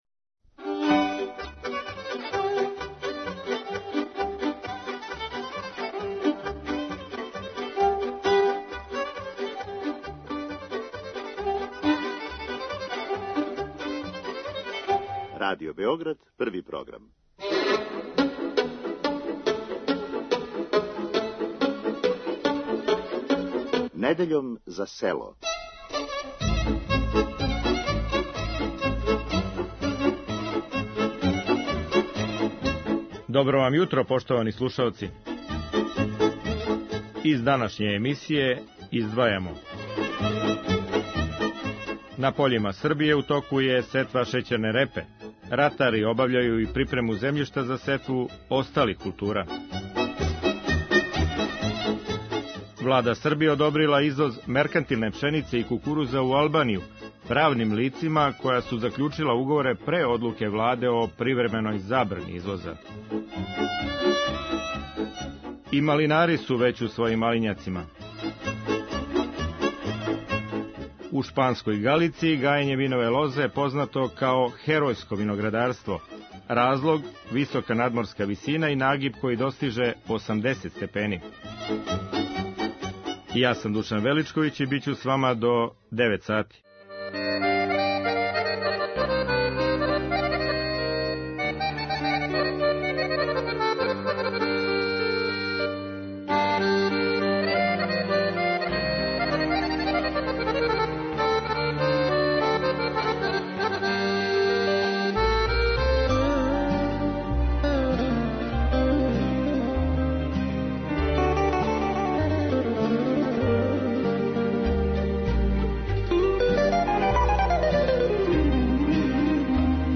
Наши репортзери били су са ратарима у околини Темерина и Малог Иђоша.